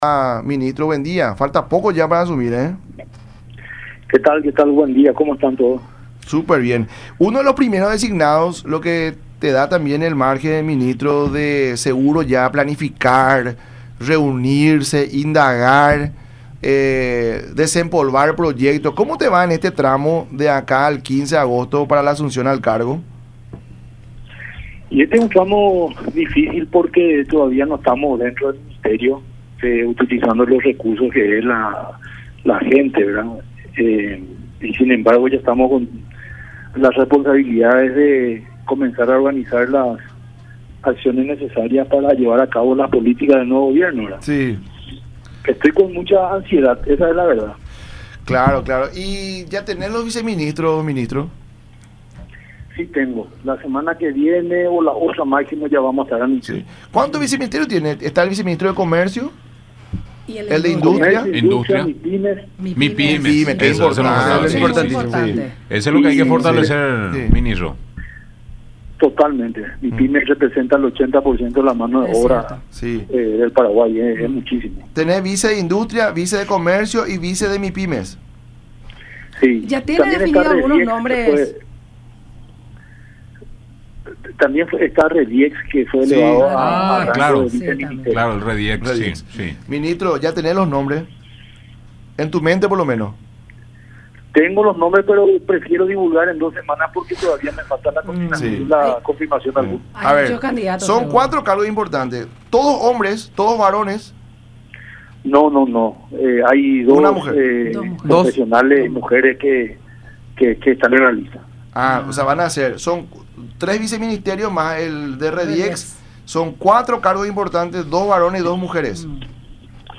“Es un tramo difícil, porque todavía no estamos en el Ministerio, sin embargo ya nos encontramos con la responsabilidad de organizar las acciones necesarias para llevar a cabo la política del nuevo gobierno, estoy con mucha ansiedad”, mencionó en el programa “La Mañana De Unión” por Unión TV y radio La Unión.